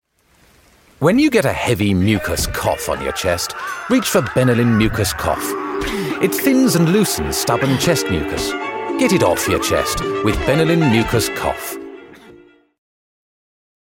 A clear, strong and authoritative delivery.